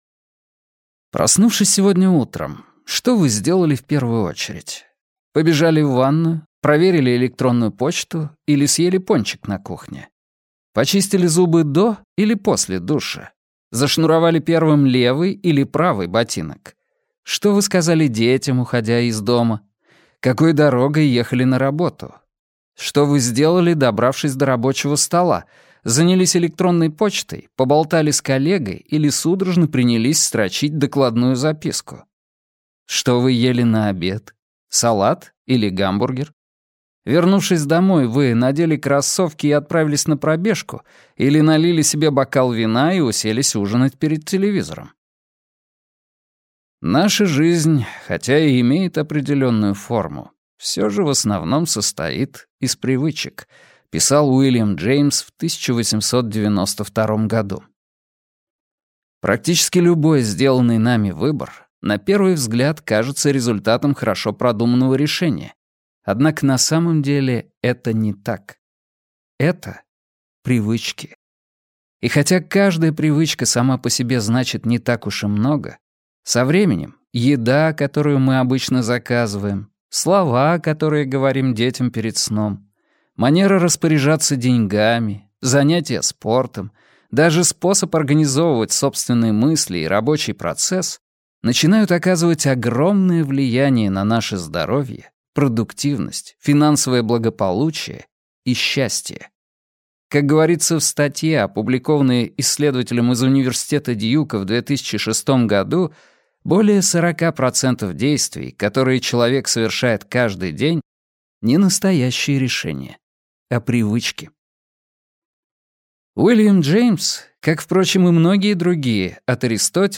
Аудиокнига Власть привычки. Почему мы живем и работаем именно так, а не иначе | Библиотека аудиокниг